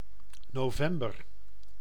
Ääntäminen
Vaihtoehtoiset kirjoitusmuodot (vanhentunut) Novembr (vanhentunut) Novembre Ääntäminen US : IPA : [noʊˈvɛm.bɚ] UK : IPA : /nəʊˈvɛm.bə/ Tuntematon aksentti: IPA : /noʊˈvɛmbəɹ/ Lyhenteet ja supistumat Nv Nov.